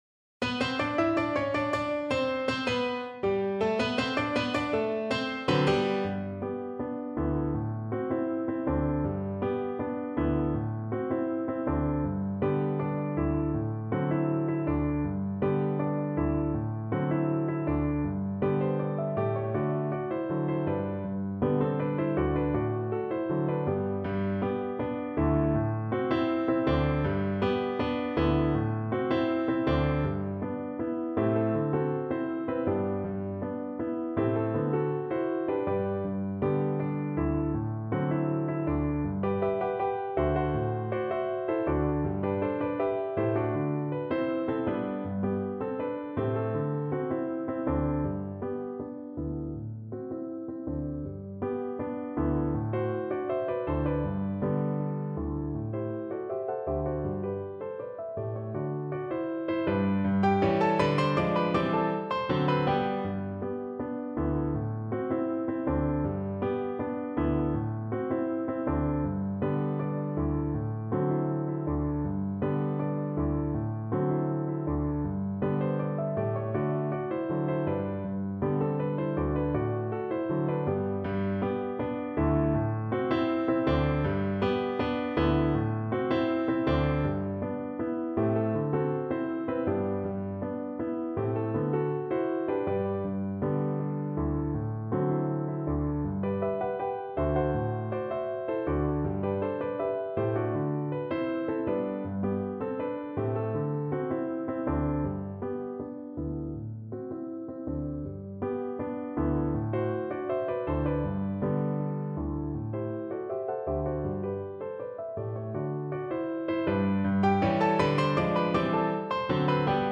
Allegretto =80
2/2 (View more 2/2 Music)
Cuban